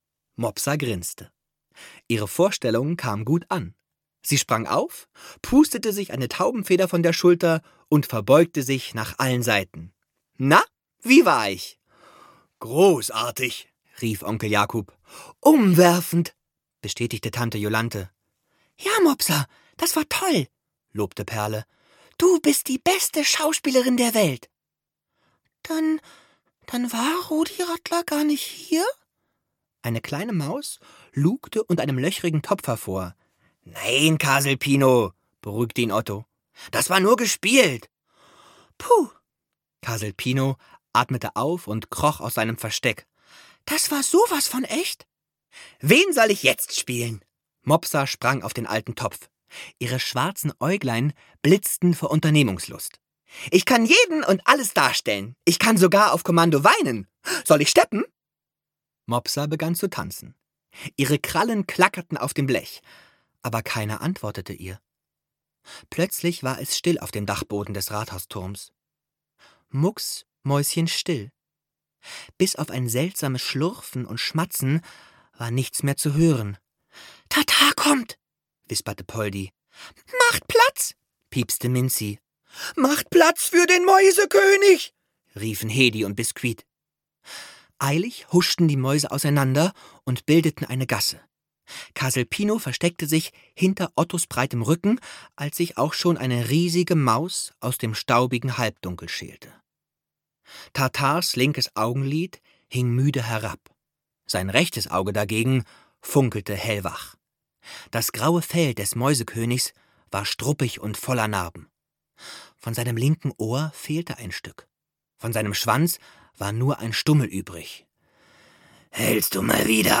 Mopsa – Eine Maus kommt ganz groß raus - Charlotte Habersack - Hörbuch